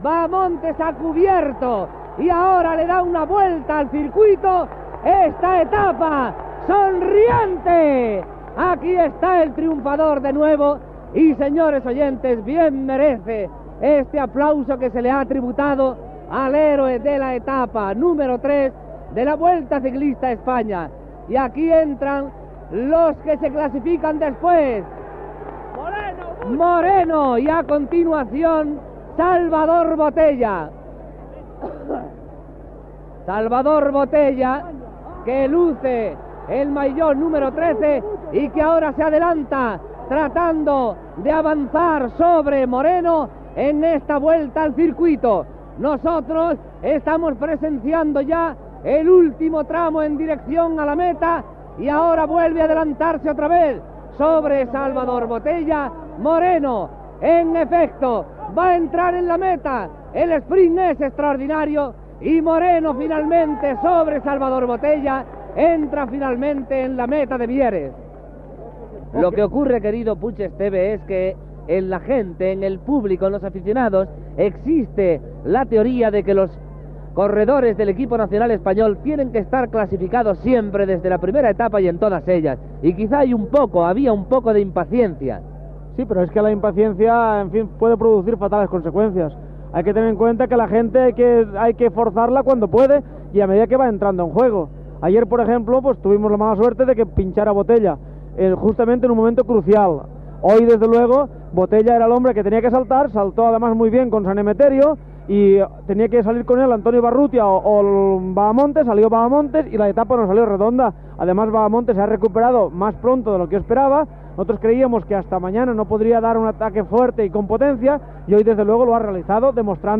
Narració de l'arribada de la tercera etapa de la Vuelta Ciclista a España, a Mieres.
Esportiu